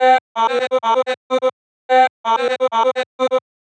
• chopped vocals 109-127 female 1 (11) - Cm - 127.wav